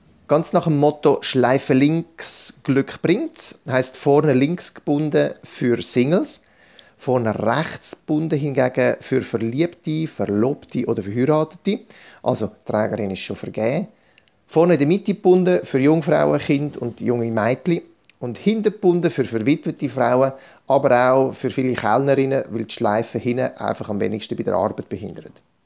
Dieses Interview gibt es auch auf HOCHDEUTSCH!!!